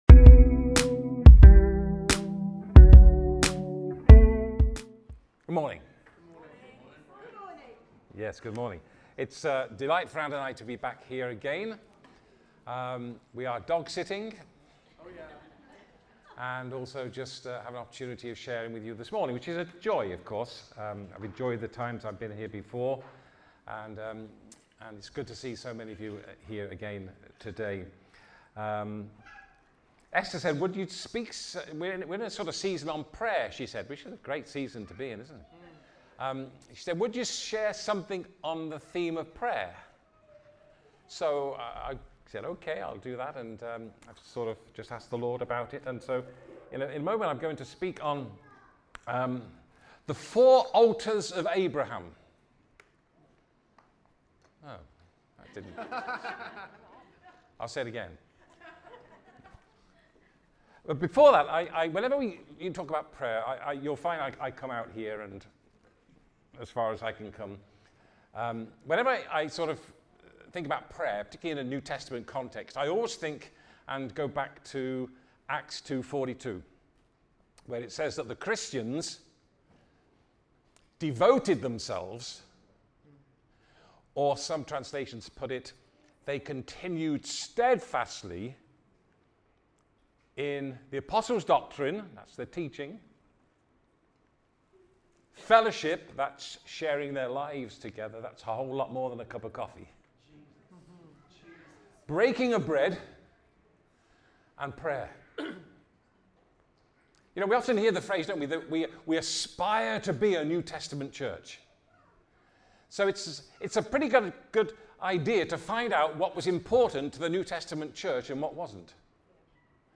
Rediscover Church Newton Abbot | Sunday Messages